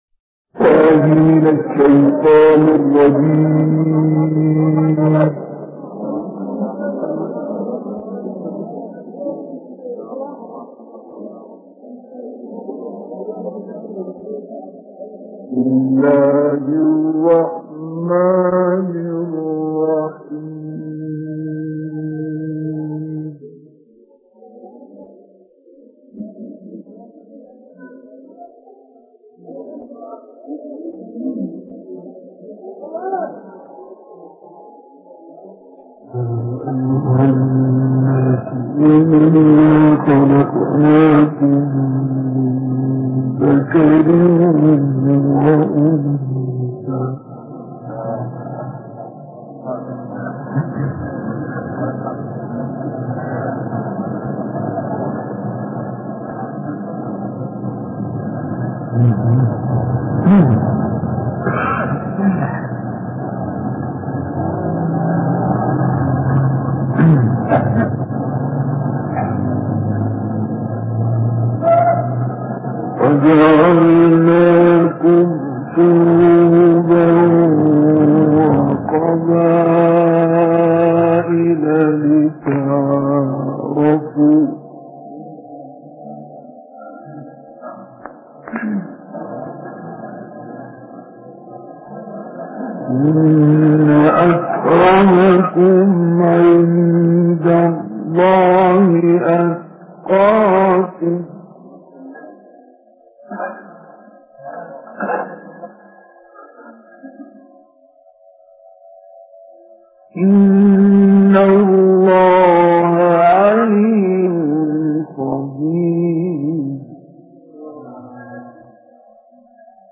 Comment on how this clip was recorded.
Surah Al-Hujurat, Qaaf, Al-Haqqa, Al-Rahman, and Al-Tariq, Alexandria 1963